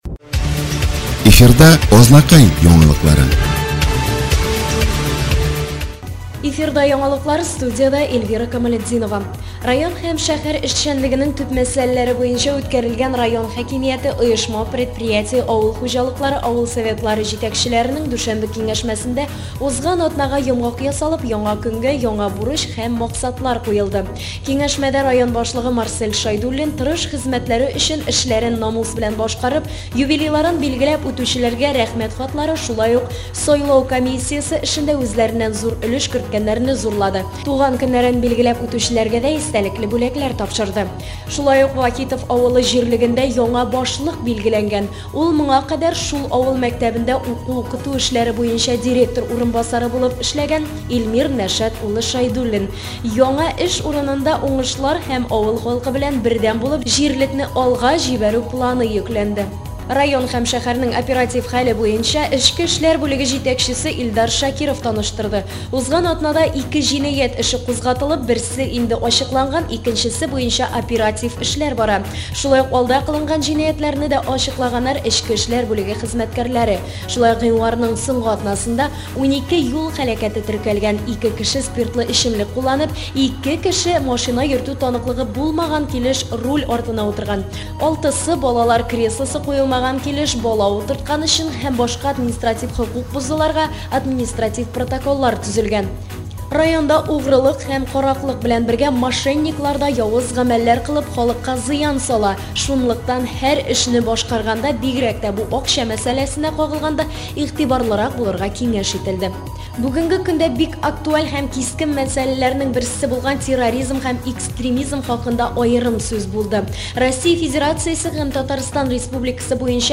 Слушать новости Азнакаевского района и города от 1 февраля 2016 года